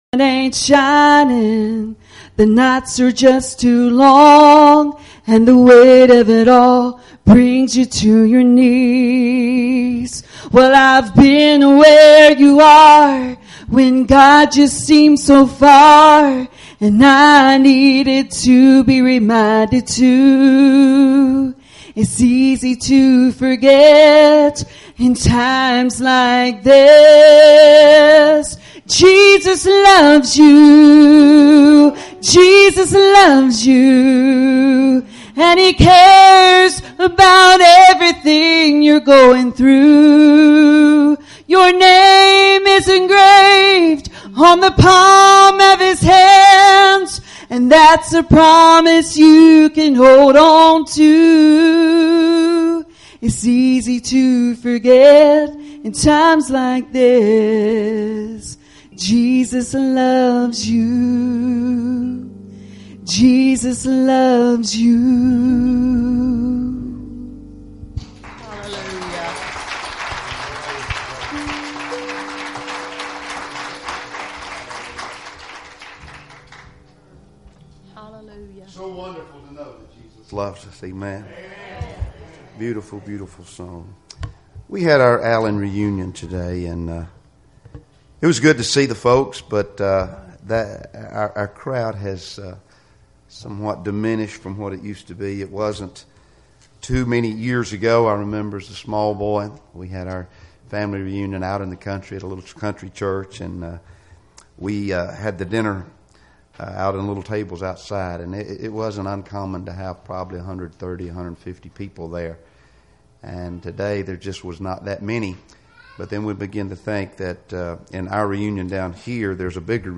Passage: Deuteronomy 31:7-8 Service Type: Sunday Evening Services